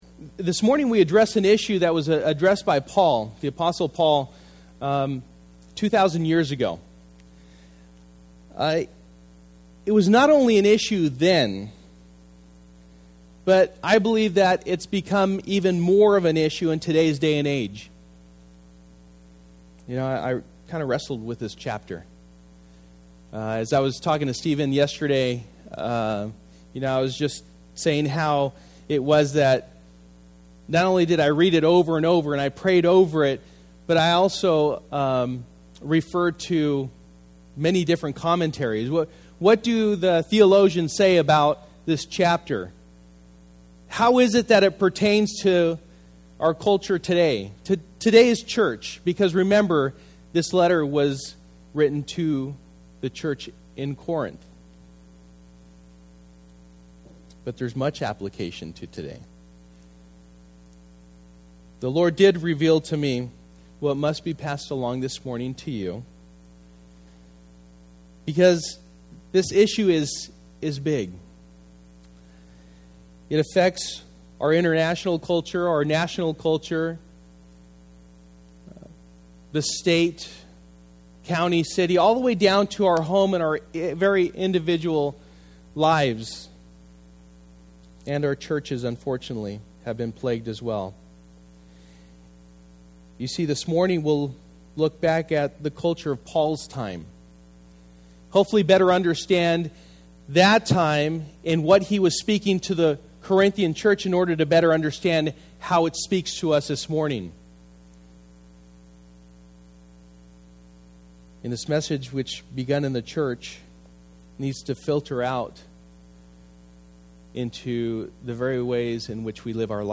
Sold Out Passage: 1 Corinthians 11:1-34 Service: Sunday Morning